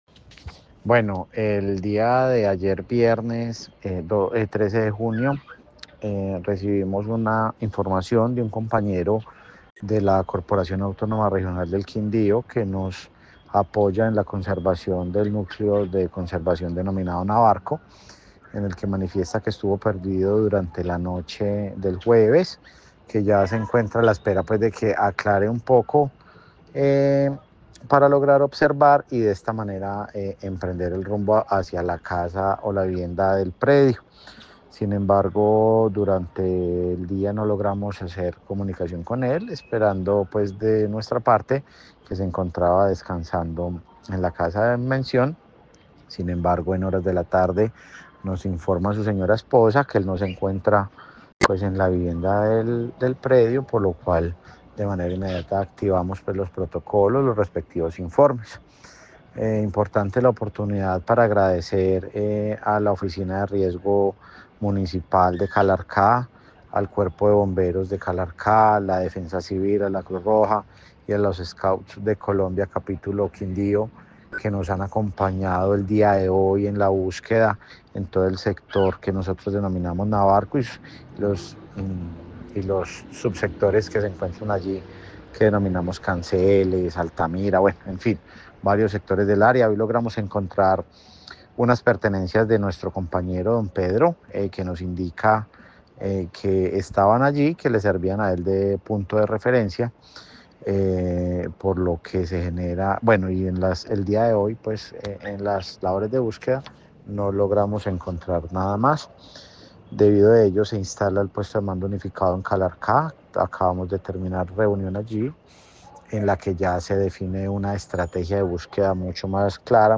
Juan Esteban Cortés, director (E) CRQ, Quindío